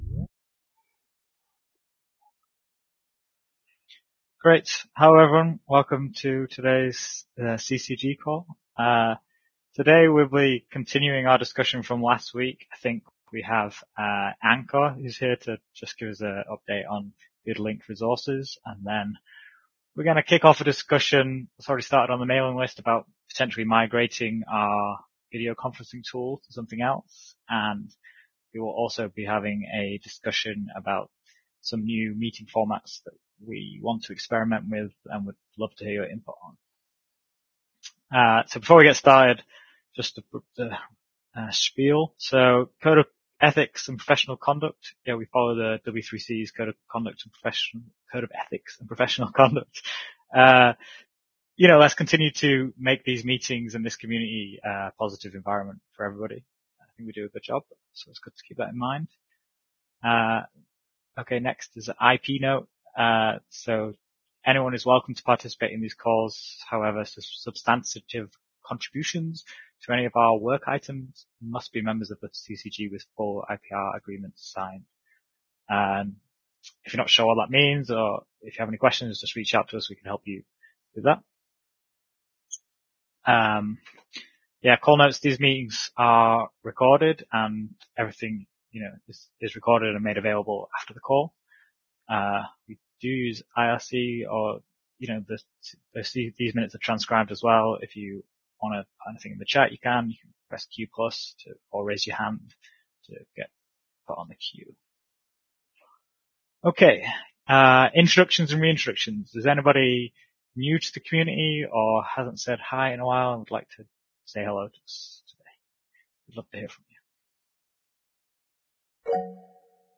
[MINUTES] W3C CCG Credentials CG Call - 2025-03-18